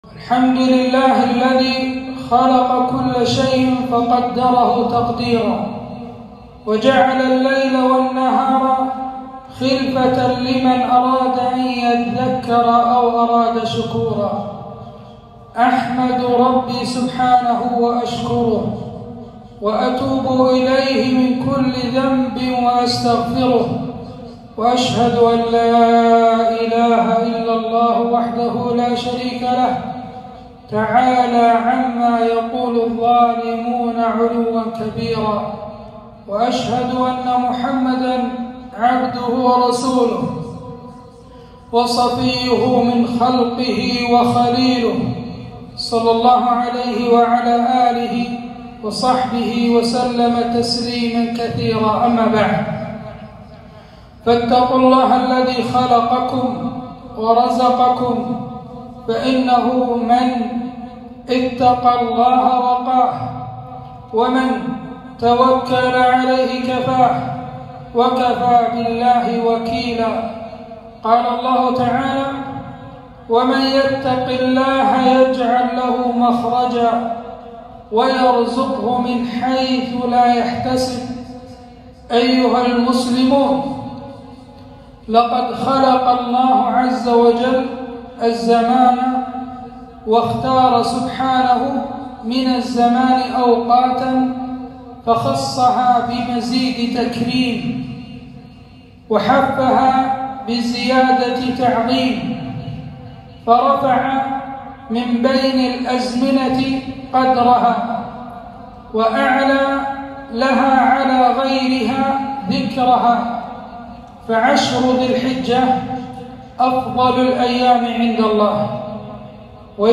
خطبة - تعظيم الأشهر الحرم